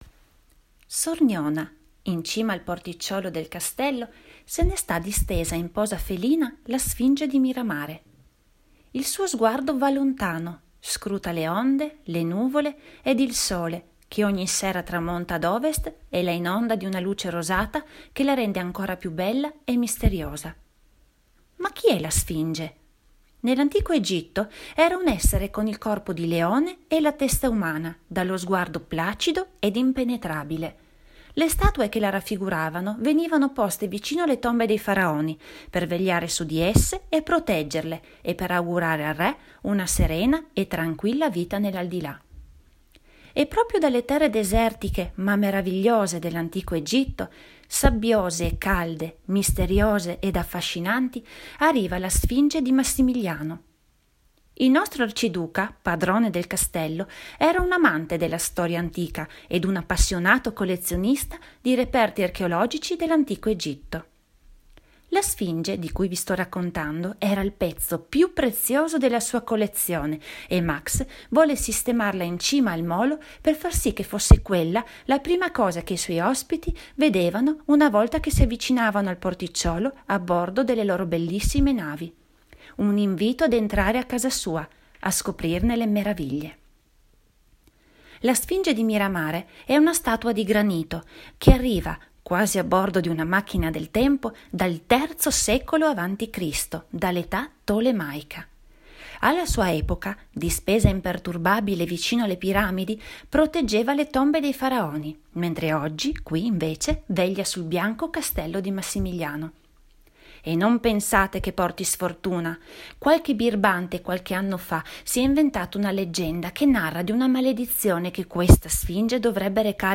La nostra seconda audiostoria è dedicata all’unica opera superstite della ricca collezione di reperti egizi di Massimiliano.